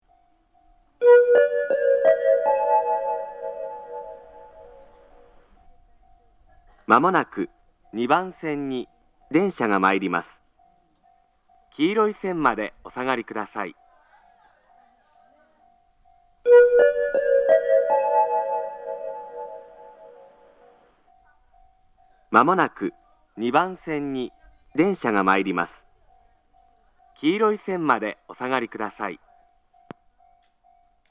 仙石旧型（男性）
接近放送
仙石旧型男性の接近放送です。同じ内容を2度繰り返します。